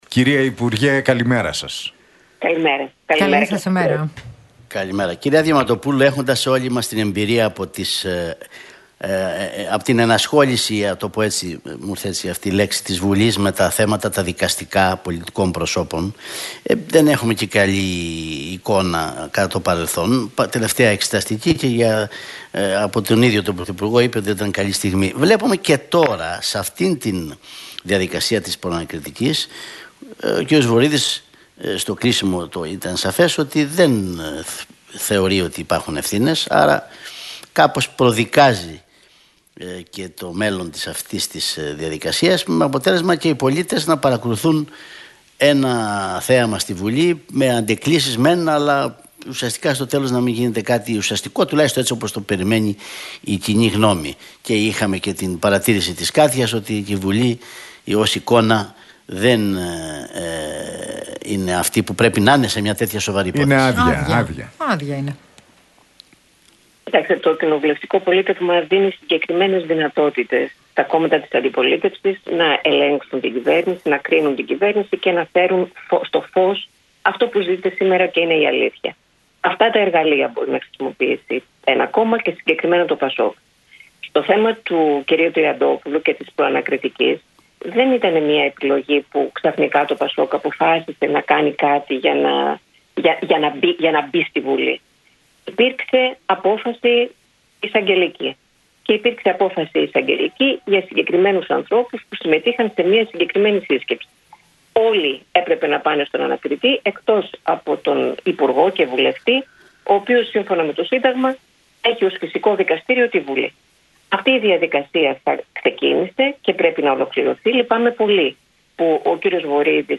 Στην εκπομπή του Realfm 97,8, η Άννα Διαμαντοπούλου, υπεύθυνη Πολιτικού Σχεδιασμού του ΠΑΣΟΚ, αναφέρθηκε στην πρόταση δυσπιστίας κατά της κυβέρνησης,